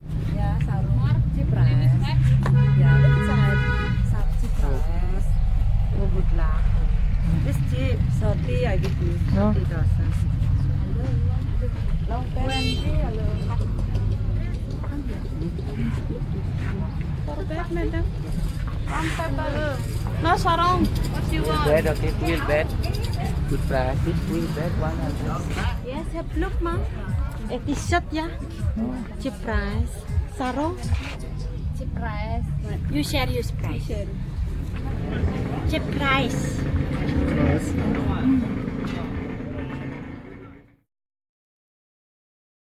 Basartreiben
Basartreiben in Ubud